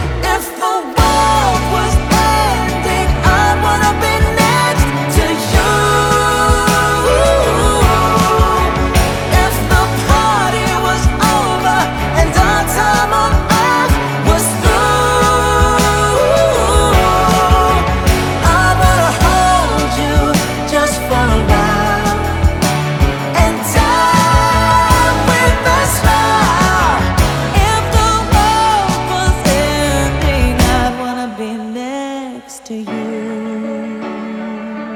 is a soft rock ballad with nostalgic 70s influences